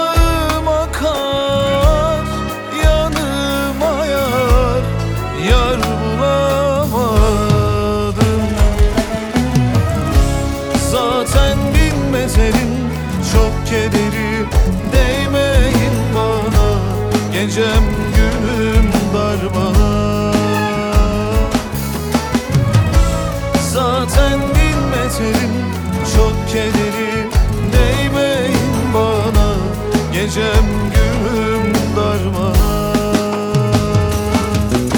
Жанр: Турецкая поп-музыка
# Arabesque